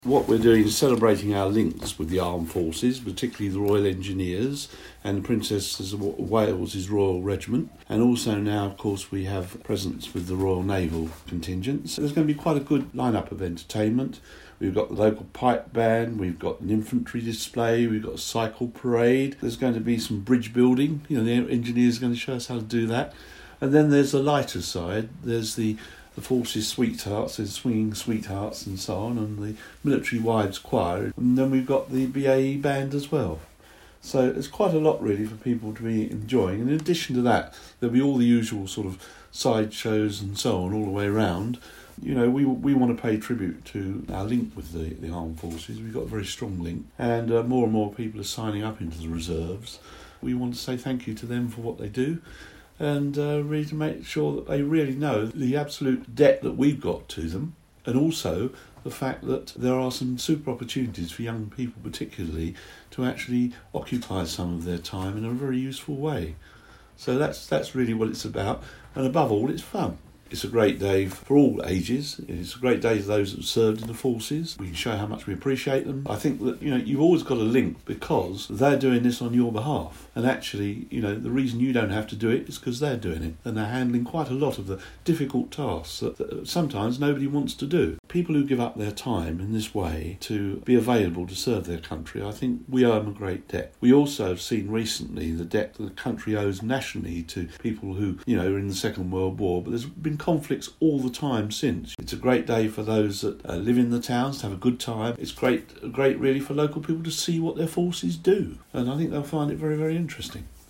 Medway Councillor Howard Doe has been telling us what to expect from the event.